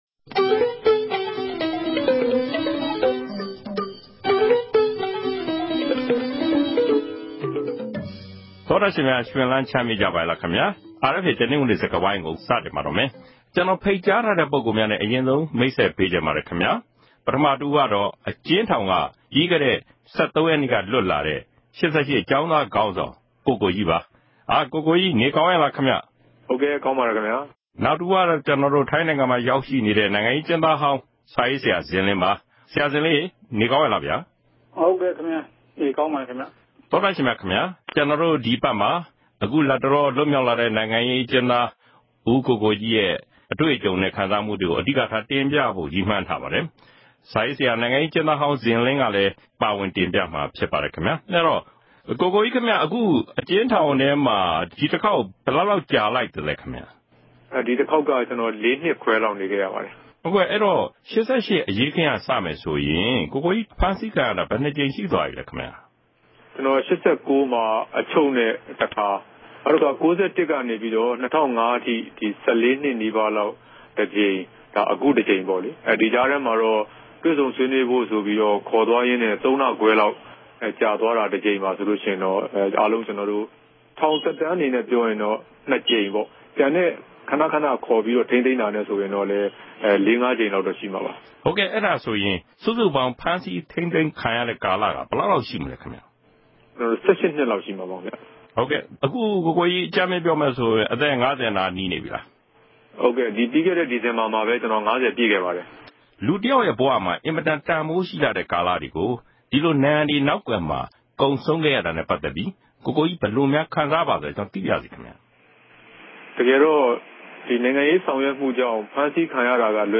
တနင်္ဂနွေစကားဝိုင်း။